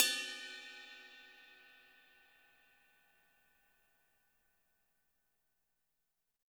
-RIDEBELL -R.wav